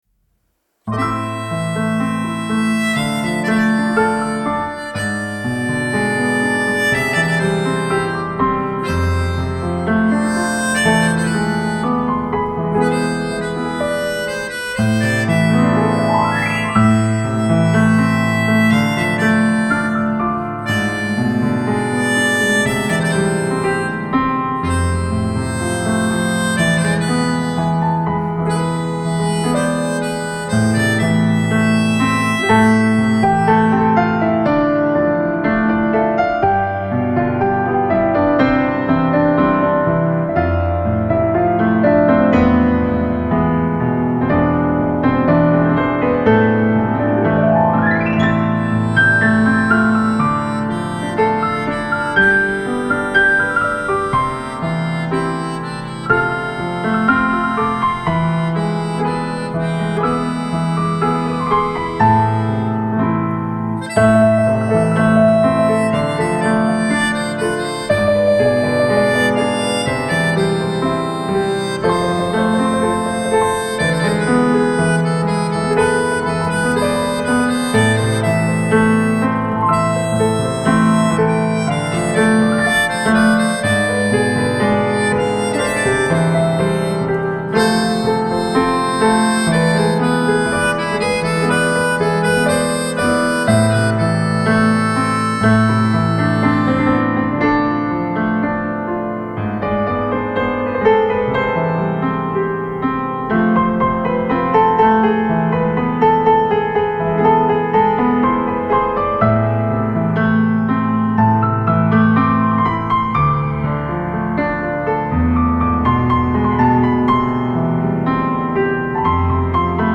آرامش بخش , پیانو , عصر جدید , موسیقی بی کلام
موسیقی بی کلام ملودیکا